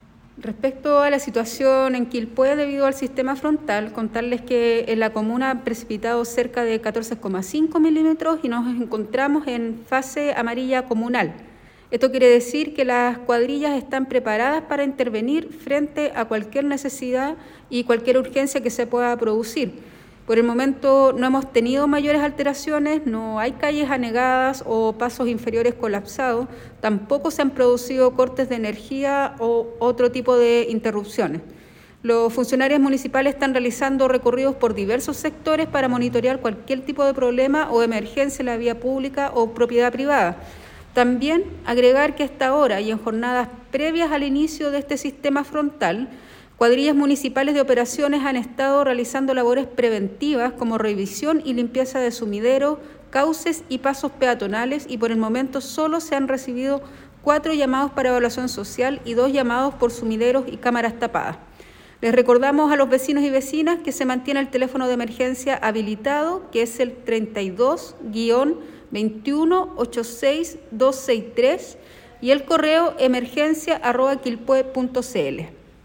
La alcaldesa de Quilpué, Valeria Melipillán, informó que hasta ahora no se ha repetido la situación de colapso vial y de pasos bajo nivel anegados y que ante el llamado de vecinos, de inmediato se han trasladado equipos municipales para destrabar los resumideros de agua, con el fin de evitar anegamiento de calles